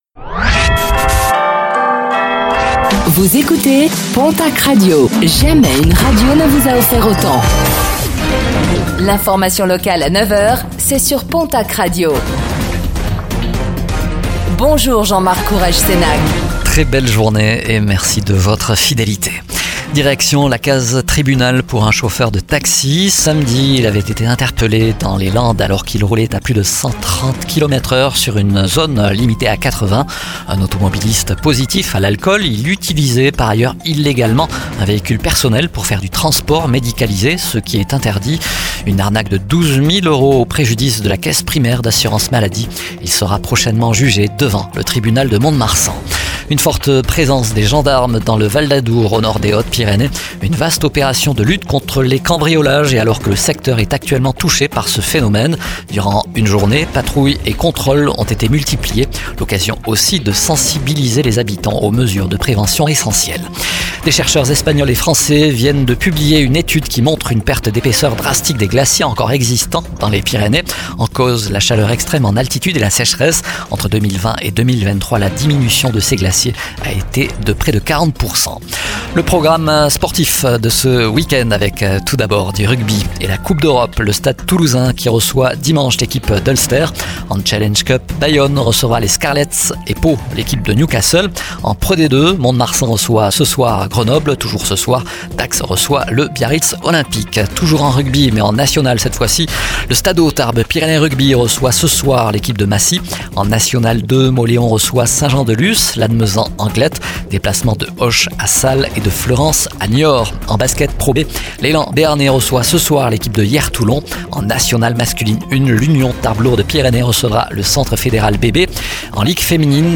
Réécoutez le flash d'information locale de ce vendredi 06 décembre 2024